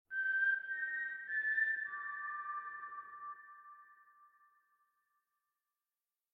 mockingjay-call_24968.mp3